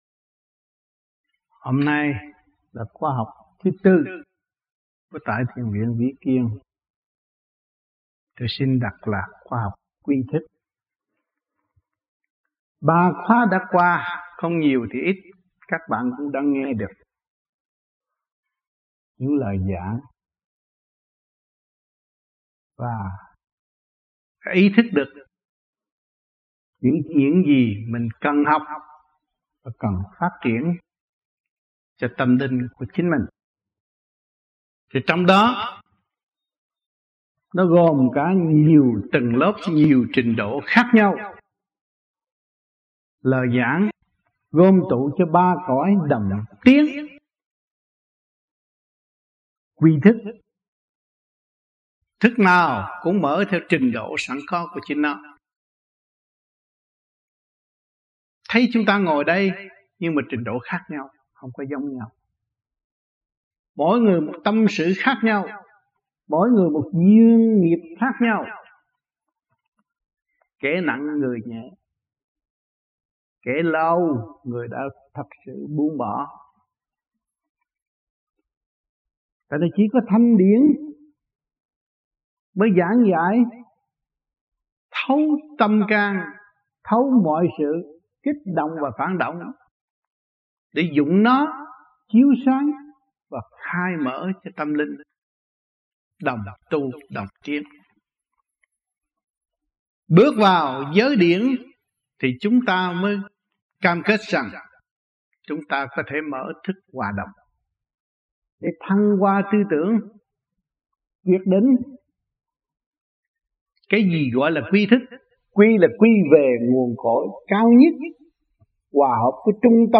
Băng Giảng 1986